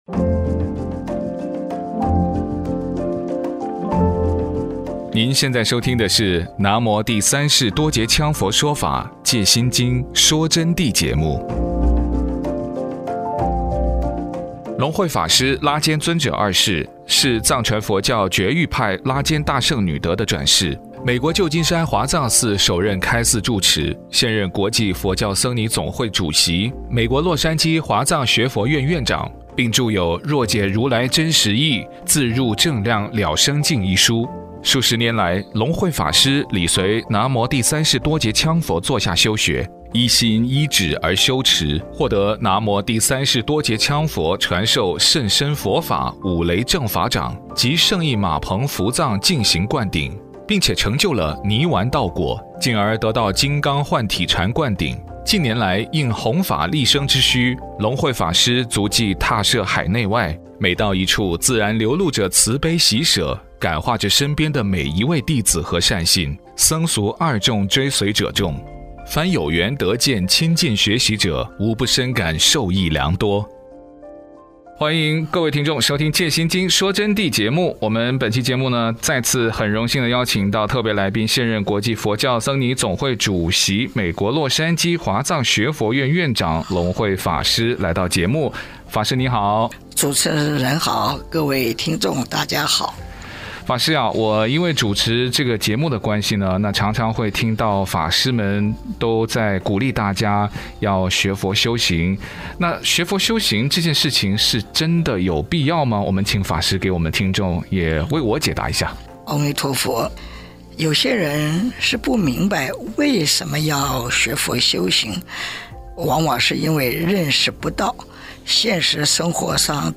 佛弟子访谈（三十五）为什么要学佛？西方极乐世界是什么样的？